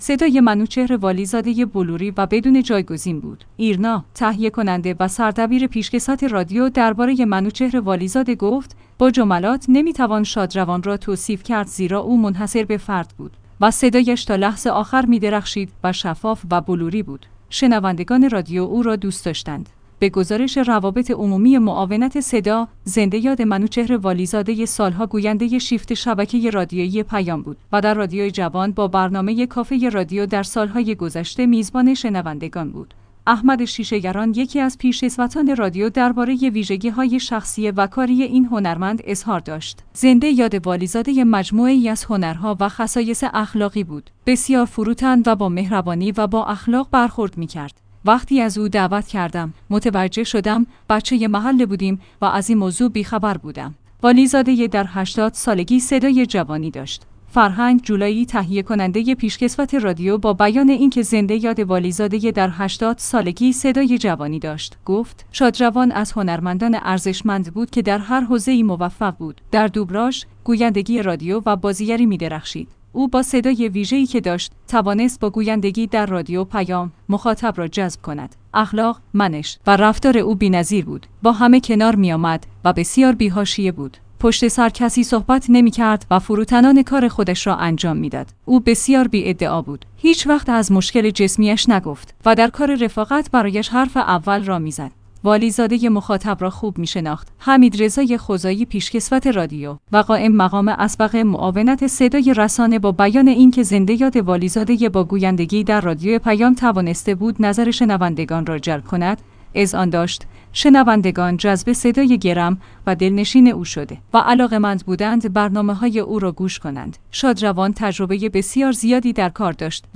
ایرنا/ تهیه‌کننده و سردبیر پیشکسوت رادیو درباره منوچهر والی‌زاده گفت: با جملات نمی‌توان شادروان را توصیف کرد زیرا او منحصر به فرد بود و صدایش تا لحظه آخر می‌درخشید و شفاف و بلوری بود.